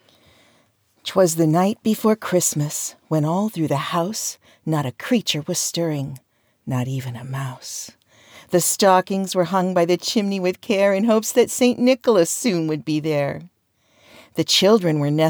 And I set up a “recording booth” in my walk in closet.
I think you’re good to go just like that. Voice quality is good and the test adjusts to perfect ACX standards with no other work.